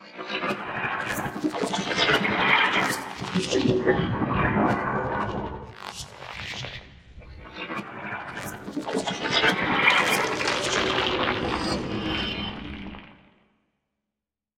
На этой странице собраны необычные аудиозаписи, имитирующие голоса и технологии внеземных существ. От причудливых сигналов до футуристических шумов – здесь есть всё для поклонников космоса и научной фантастики.
Звук голоса пришельца